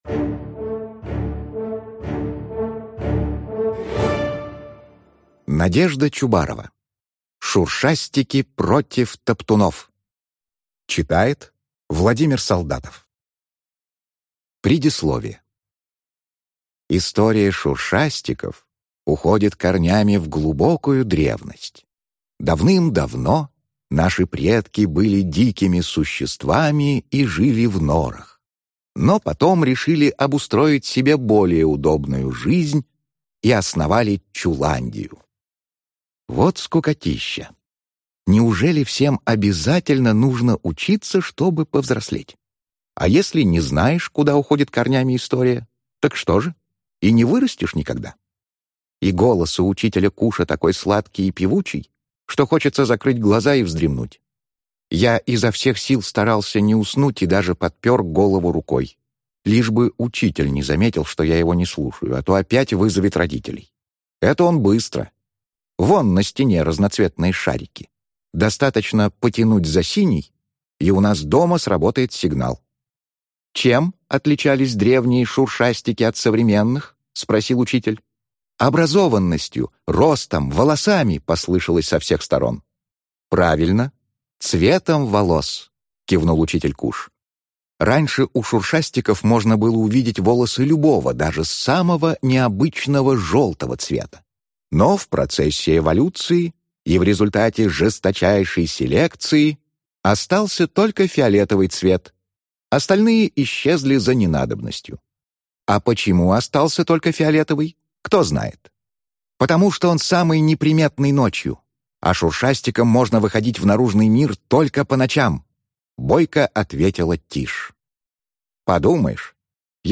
Аудиокнига Шуршастики против топтунов | Библиотека аудиокниг